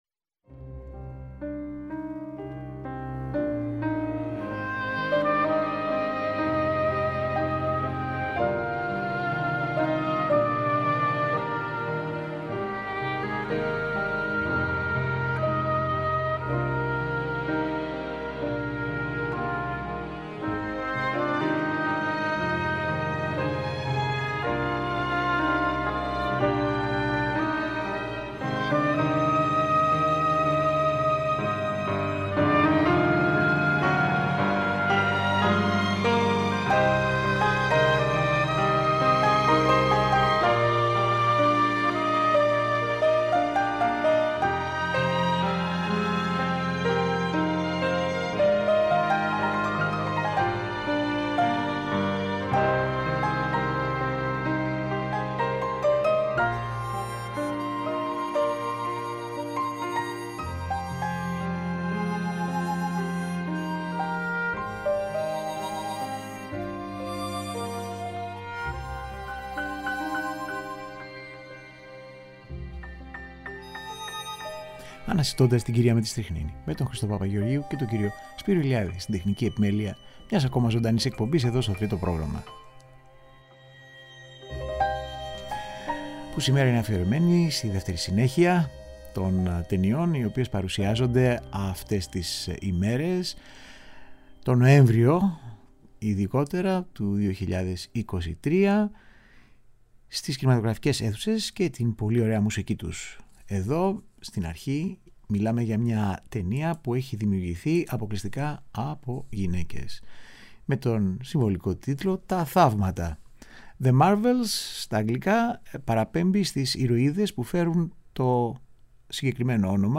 Μουσικές από κινηματογραφικές κυκλοφορίες του Νοεμβρίου – μέρος 2ο
Κινηματογραφικη Μουσικη